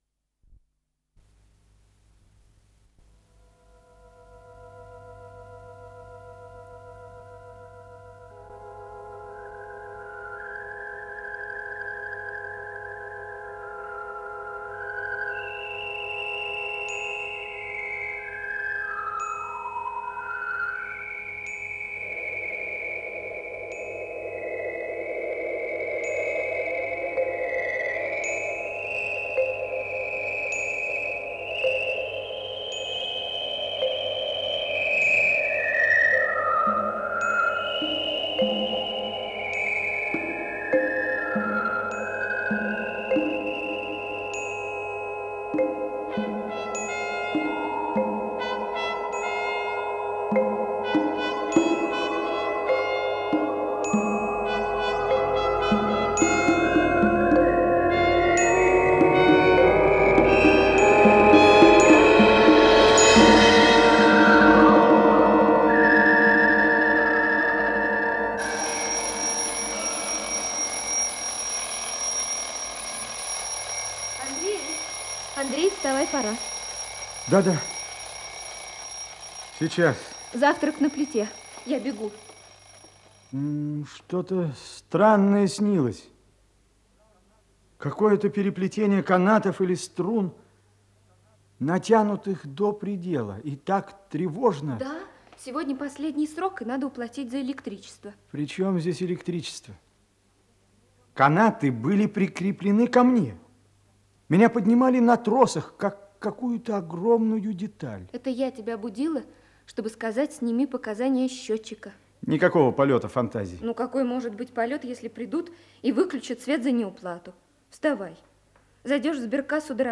Исполнитель: Артисты московских театров
Радиопьеса